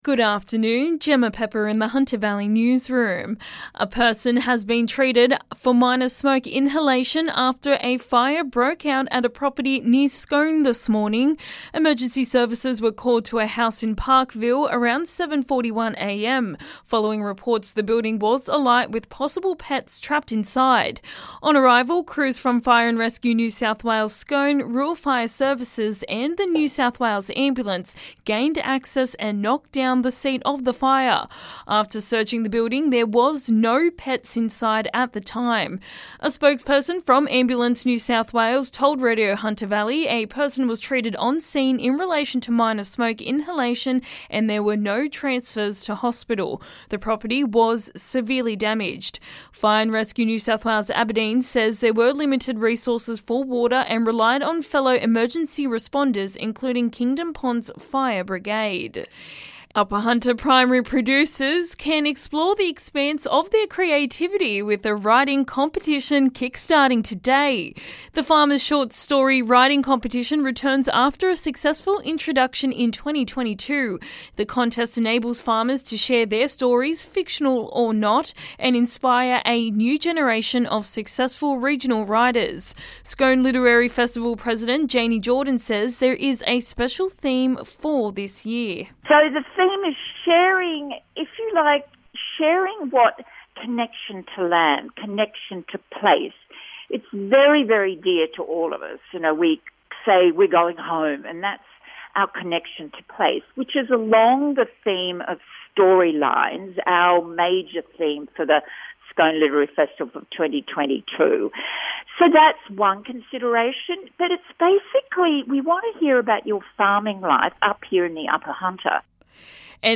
LISTEN: HUNTER VALLEY LOCAL NEWS HEADLINES 18/01/22